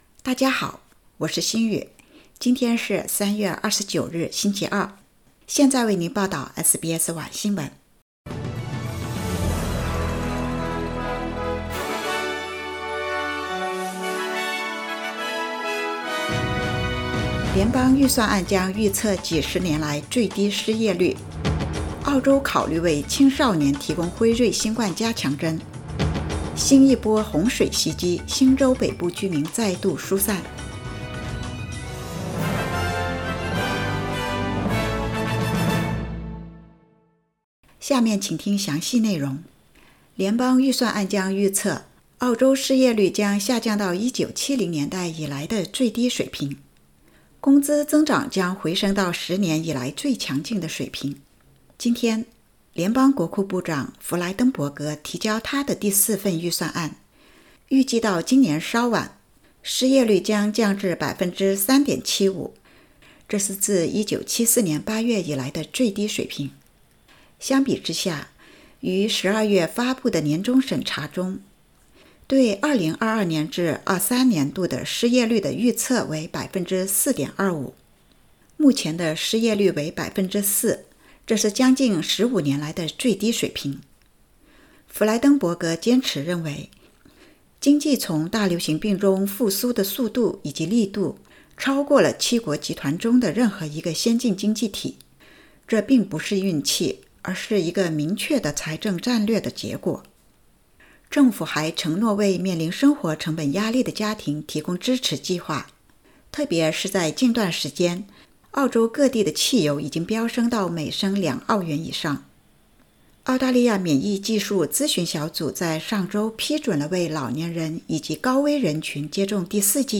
SBS晚新聞（2022年3月29日）
SBS Mandarin evening news Source: Getty Images